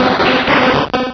Cri de Krabboss dans Pokémon Rubis et Saphir.